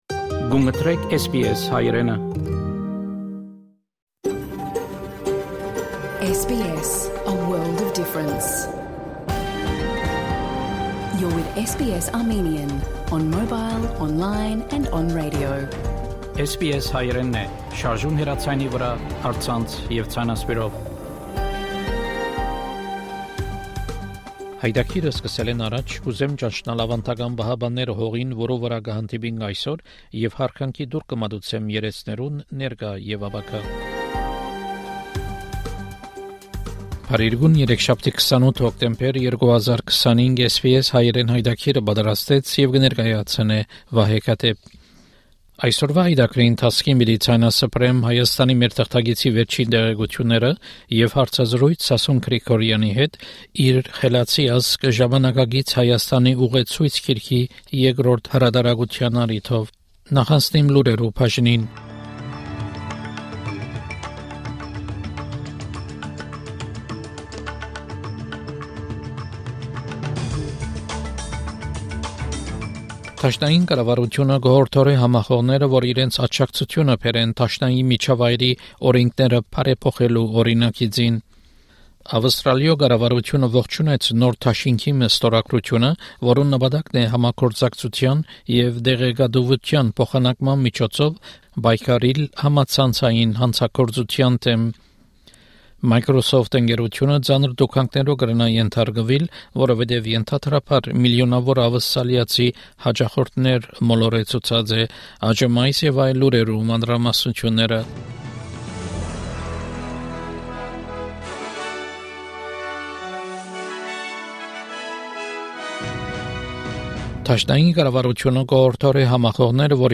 SBS Armenian news bulletin from 28 October 2025 program.